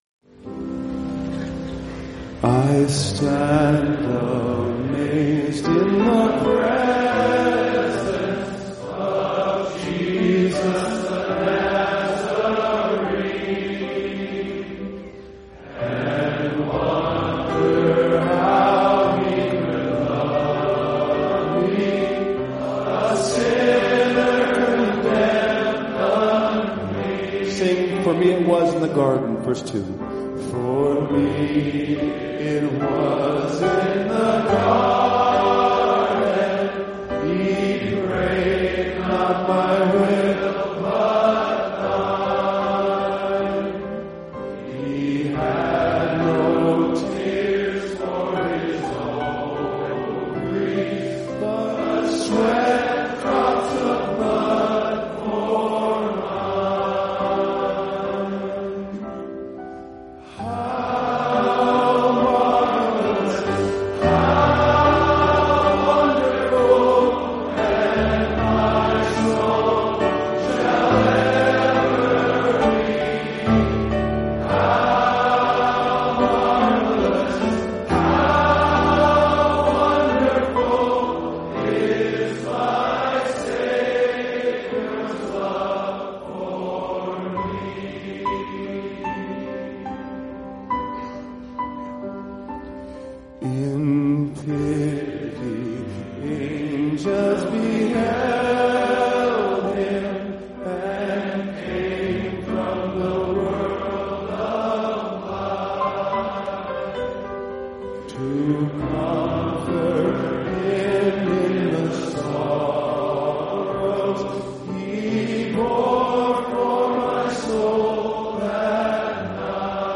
Congregational singing at The Shepherd’s Conference for Pastors, Grace Community Church, Sun Valley, California.